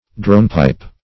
Meaning of dronepipe. dronepipe synonyms, pronunciation, spelling and more from Free Dictionary.
Dronepipe \Drone"pipe`\, n. One of the low-toned tubes of a bagpipe.